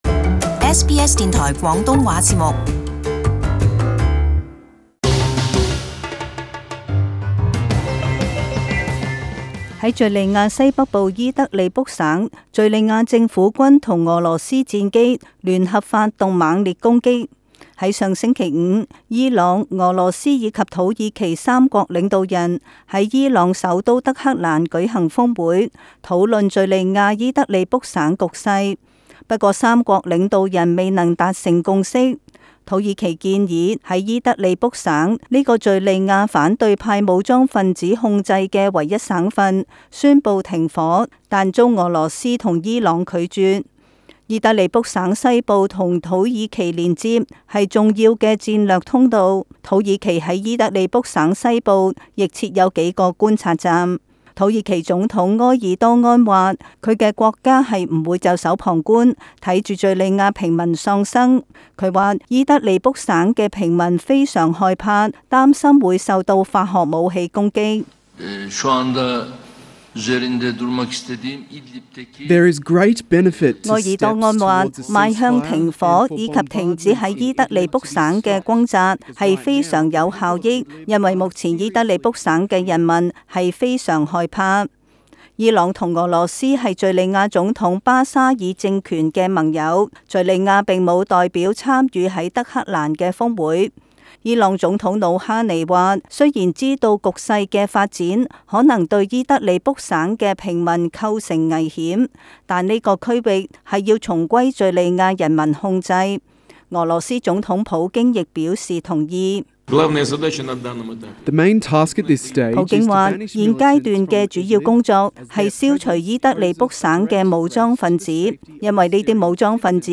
【時事報導】敘利亞伊德利卜再遭攻擊